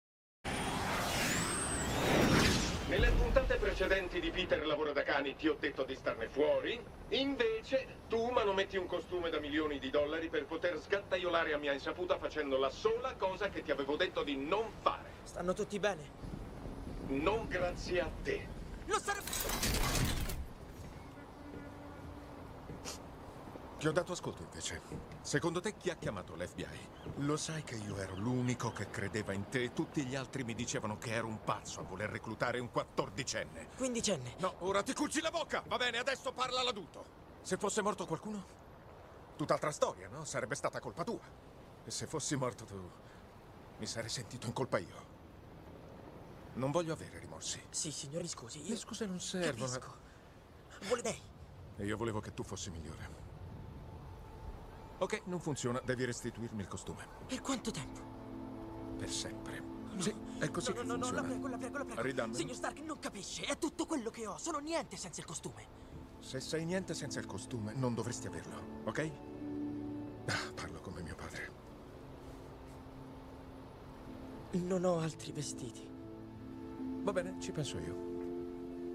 voce di Angelo Maggi nel film "Spider-Man: Homecoming", in cui doppia Robert Downey Jr.